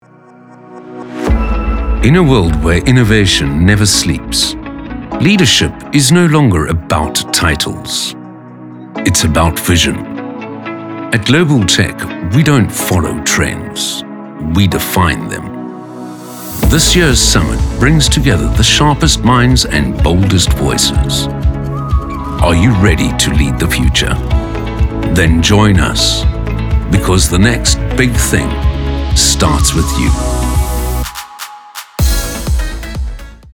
articulate, authentic, captivating, confident, Deep, energetic, friendly
30-45, 45 - Above
Corporate 2